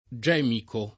gemico [ J$ miko ], ‑chi